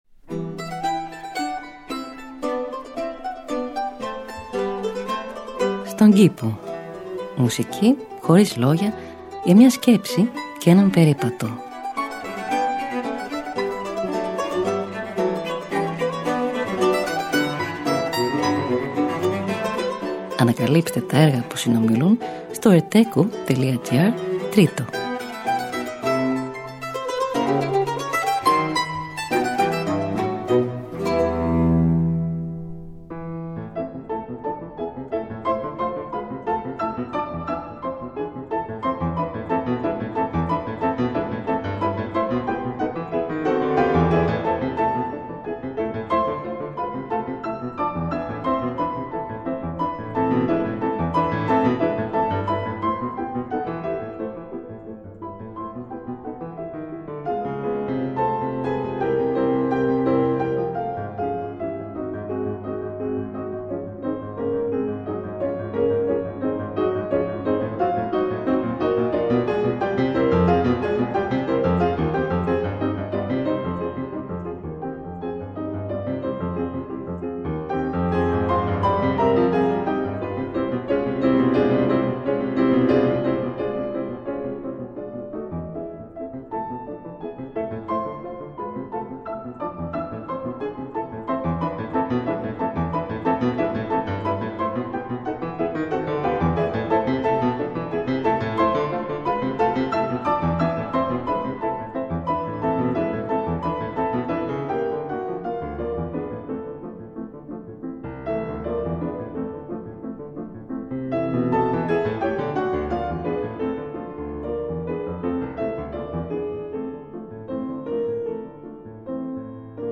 Μουσική Χωρίς Λόγια για μια Σκέψη και έναν Περίπατο.
Arrange for mandolin and continuo